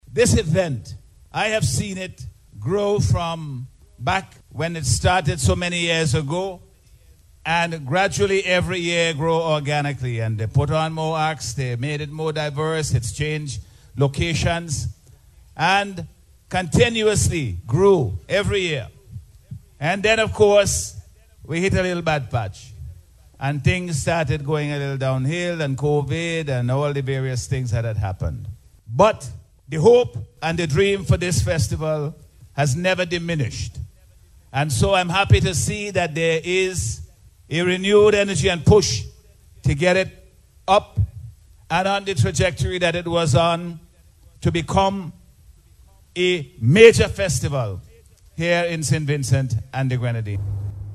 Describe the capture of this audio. The Prime Minister delivered remarks at the official opening of the Festival Saturday night.